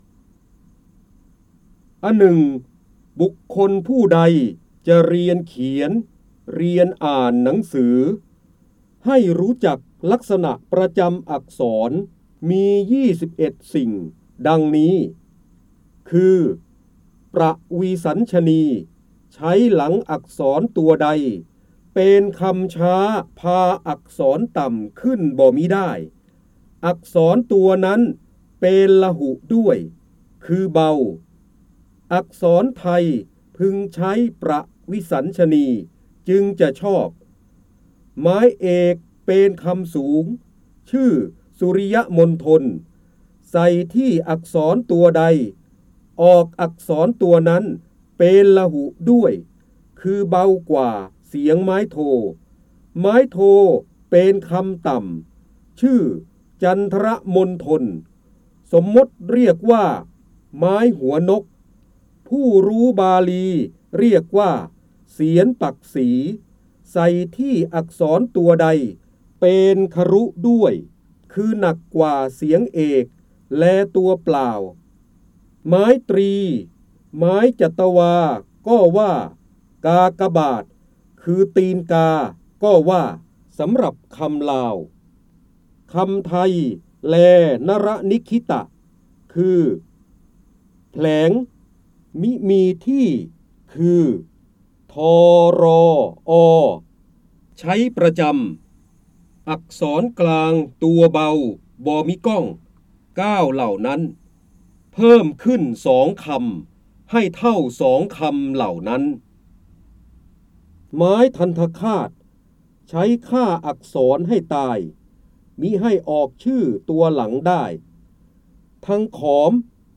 เสียงบรรยายจากหนังสือ จินดามณี (พระเจ้าบรมโกศ) อหนึ่งบุกคลผู้ใดจเรียนเขียน เรียนอ่านหนังสือ